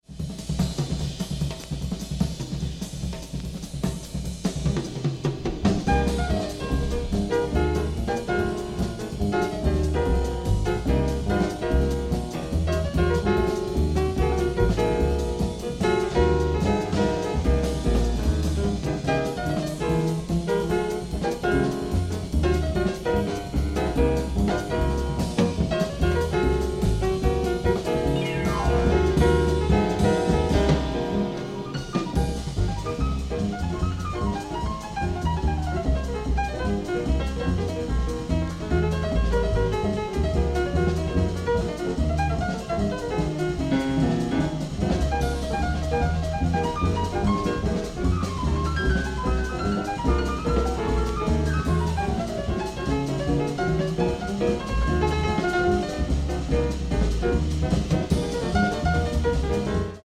Heavy live LP